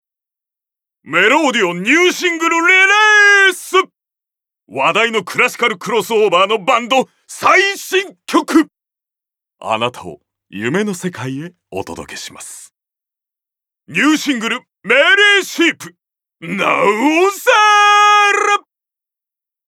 ボイスサンプル
ナレーション２